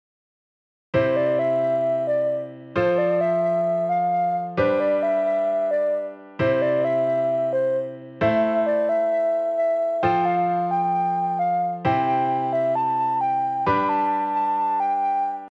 Aメロが「上がって下がる」という音形なので、Bメロは「下がって上がる」にしてみた例です。
AメロはC～Aの音域に収まっていて、全体的に低い音が多いです。
一方、BメロはD～Bの音域で、全体的に高い音が多くなっています。
ここまででご紹介したサンプルはすべて、Aメロ＝C⇒F⇒G⇒C、Bメロ＝Am⇒F⇒C⇒Gです。